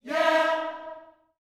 YEAH E 4D.wav